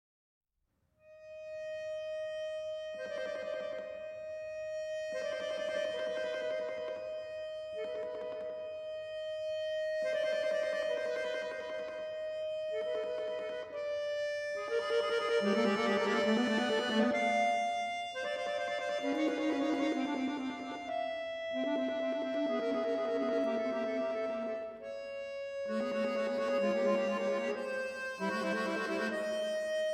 honoured Alfred Schnittke's death with a Fantasia for bayan.